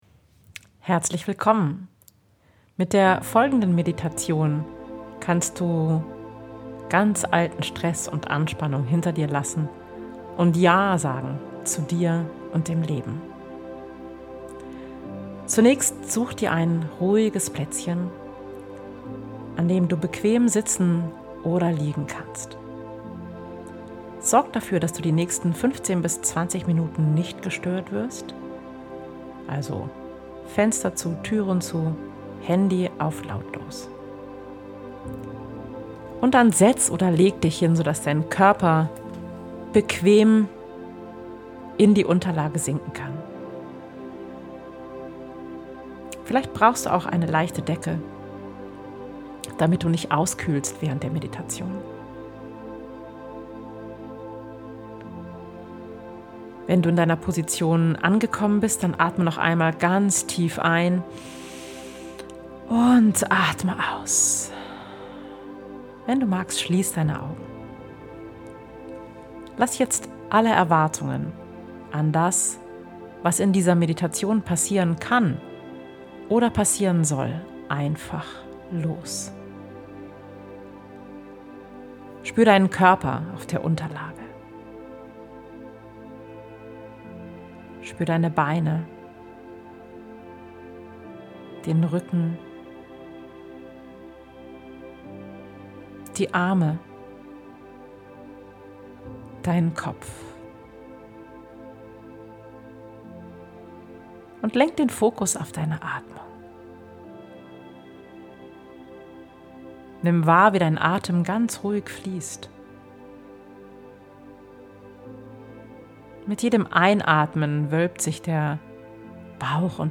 Meditation "JA! zu Dir!"